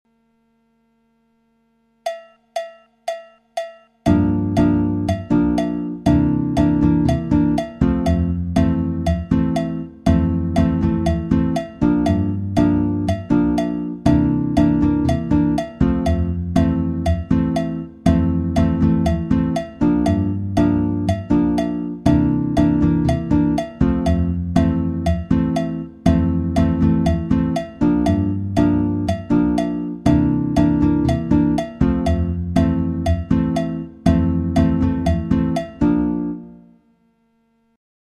Comme support de rythme j'ai mis un clic à la croche, c'est à dire 2 pulsations par temps et cela dans le but de bien décortiquer la tourne de guitare.
La batida de Toquinho 2